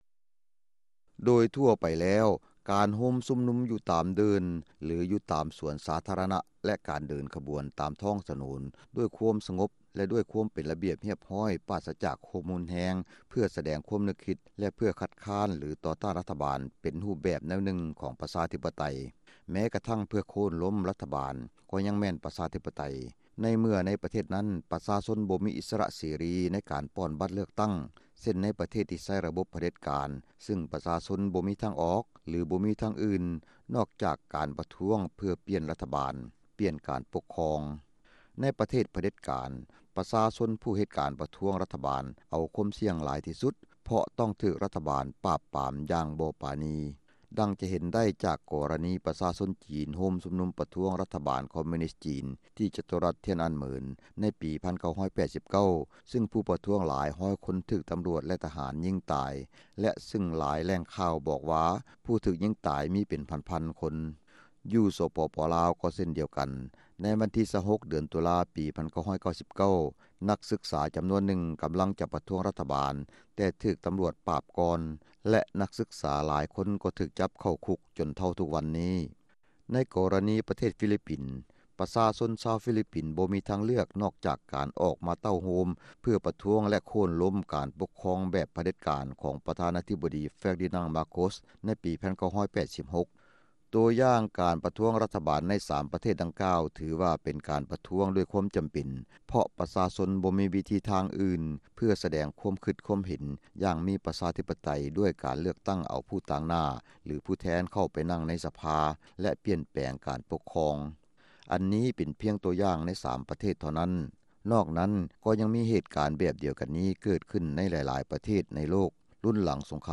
ບົດວິເຄາະ